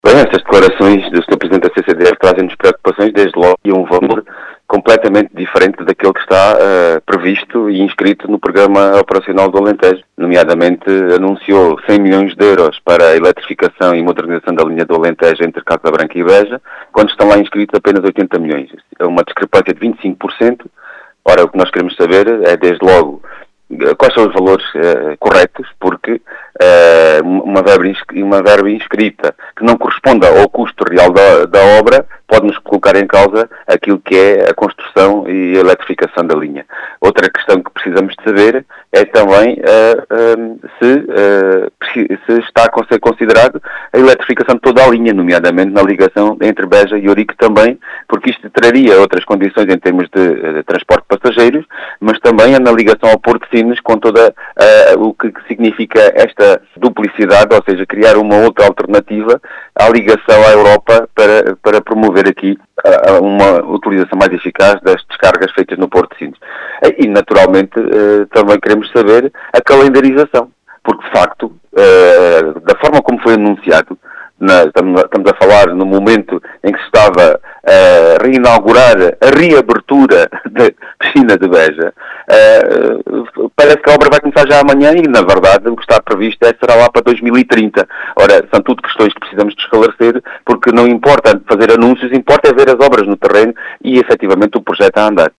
As explicações foram deixadas na Rádio Vidigueira, pelo deputado João Dias.